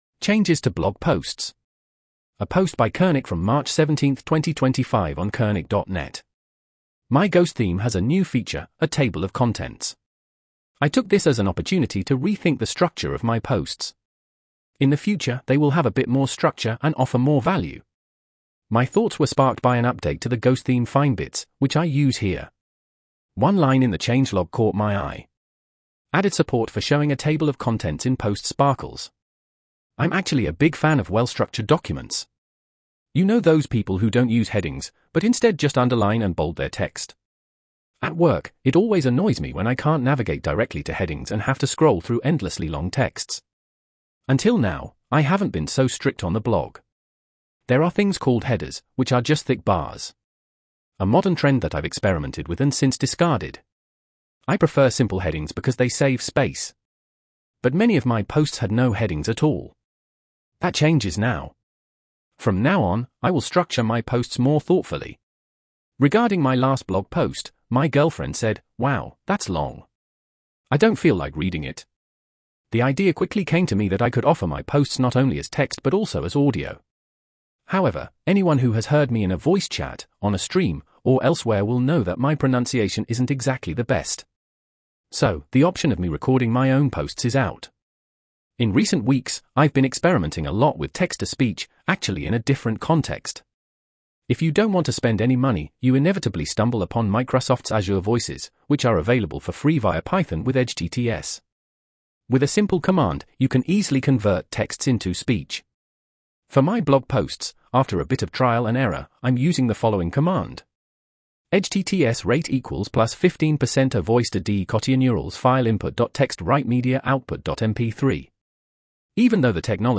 edge-tts –rate=+15% –voice de-DE-KatjaNeural –file input.txt –write-media output.mp3
Even though the technology has advanced massively, it’s not yet perfect. It gets tricky, for example, when individual English words appear in a sentence.